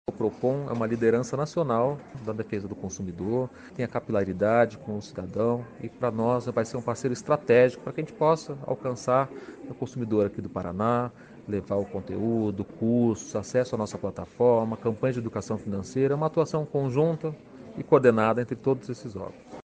Sonora do diretor de sustentabilidade